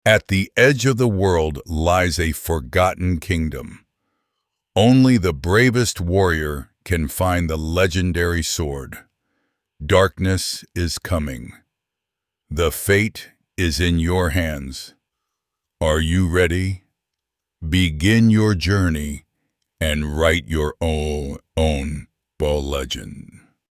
voiceDavidDeep, authoritative male voice ideal for epic tone.
style0.6Adds cinematic emphasis and dramatic expression.
speed0.85Slightly slower pace creates gravity and suspense.
Sample generated audio:
Power⭐⭐⭐⭐⭐Deep tone with strong projection.
Dramatic impact⭐⭐⭐⭐⭐Expressive enough without overacting.
Clarity⭐⭐⭐⭐⭐Strong articulation and readability.